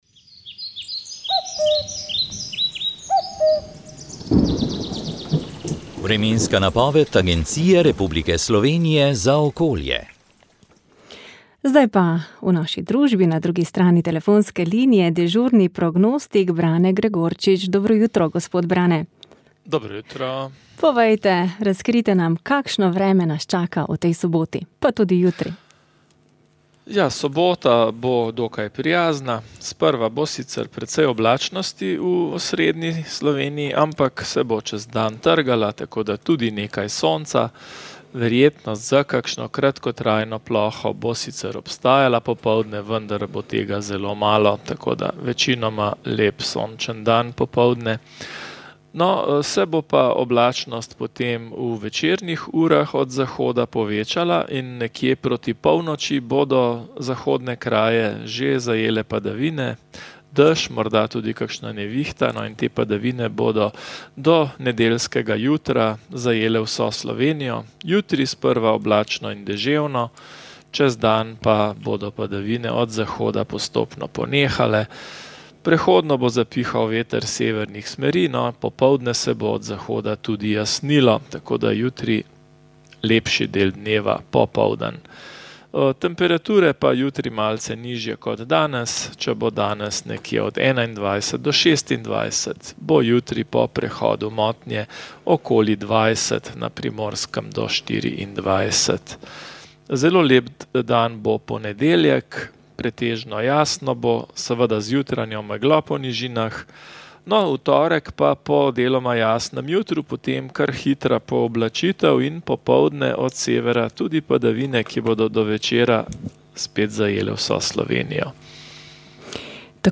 Letos junija je potekal simpozij ob 80. obletnici začetka II. svetovne vojne v organizaciji Študijskega centra za narodno spravo in Parka vojaške zgodovine Pivka.